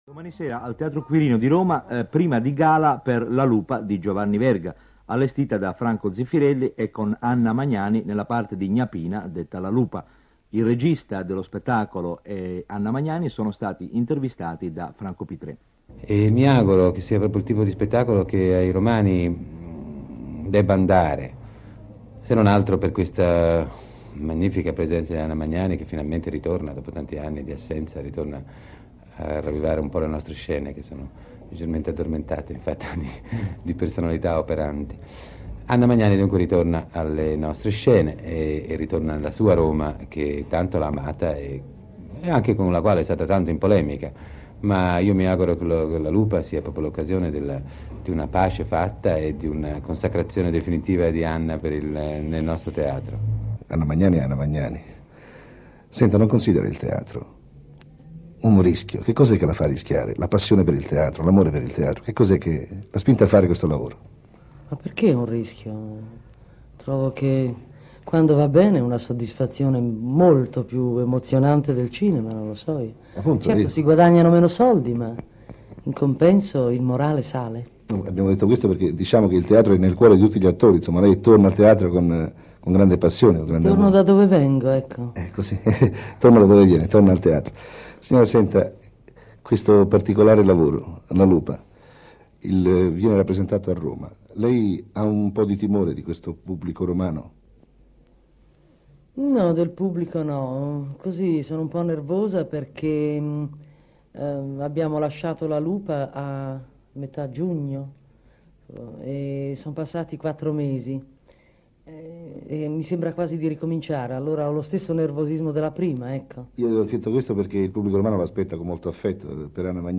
Intervista a Zeffirelli e Anna Magnani, Teatro Quirino